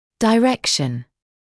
[dɪ’rekʃn], [daɪ’rekʃn][ди’рэкшн], [дай’рэкшн]направление; указание, предписание